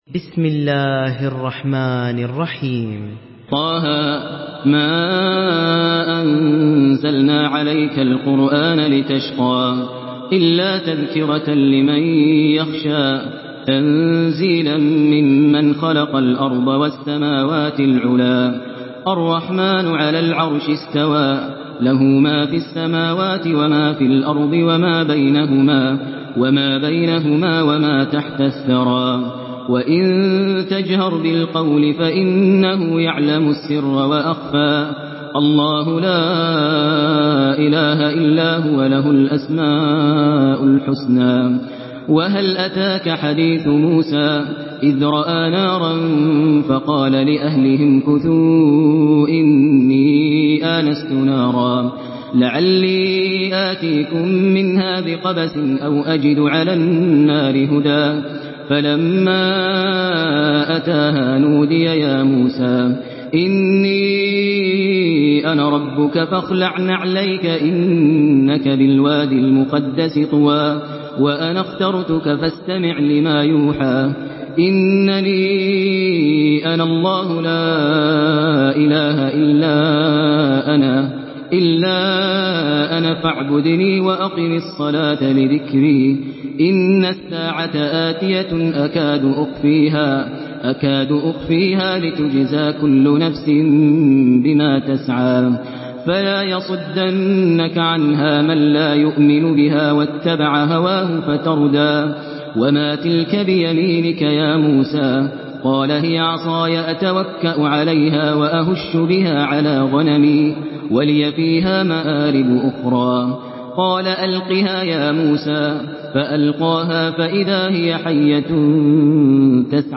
Surah ত্বা-হা MP3 by Maher Al Muaiqly in Hafs An Asim narration.